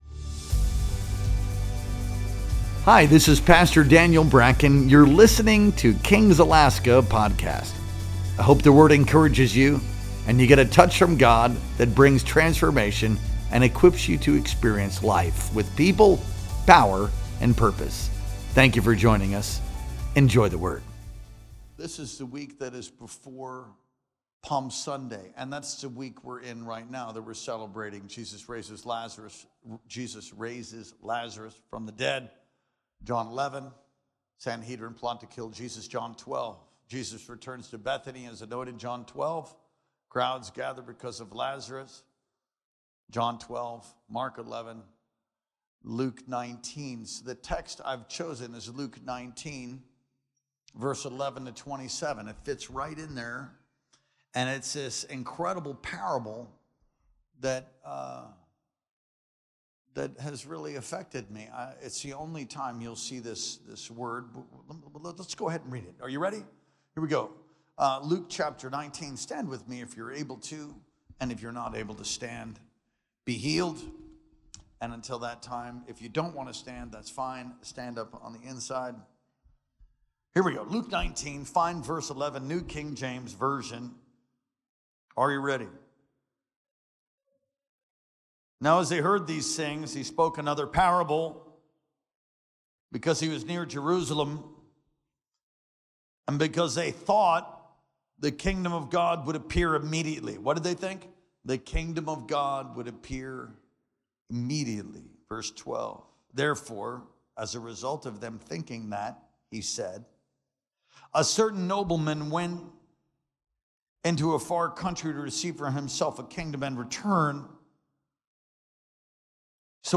Our Wednesday Night Worship Experience streamed live on April 9th, 2025.